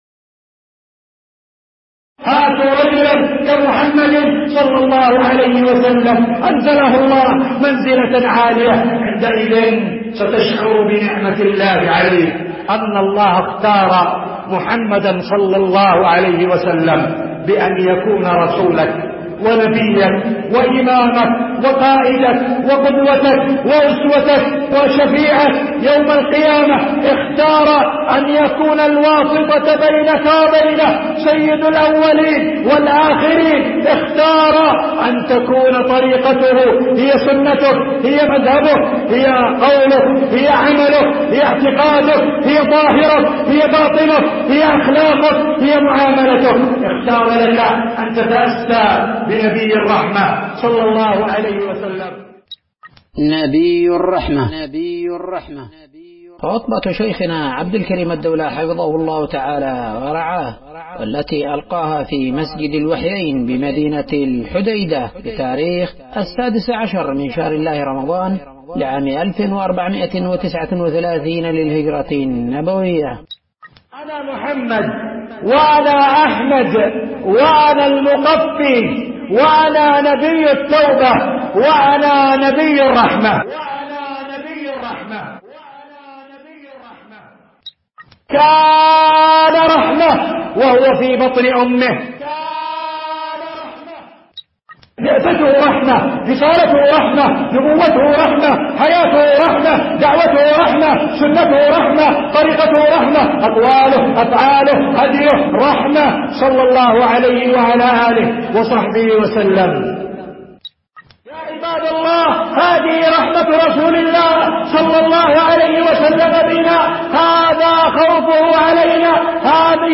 خطبة نبي الرحمة